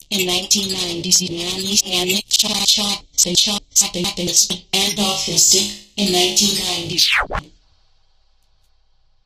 Added voice clip for malfunctioning AI